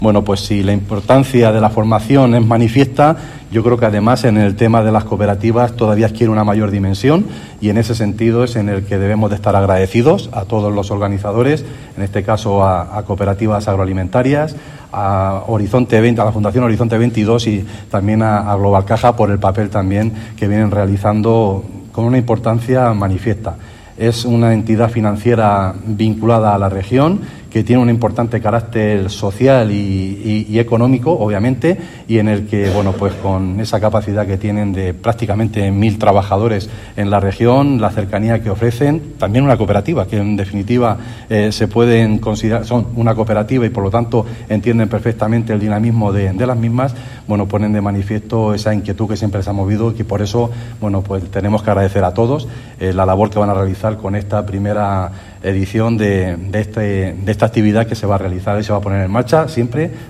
Julián Martínez-consejero de Agricultura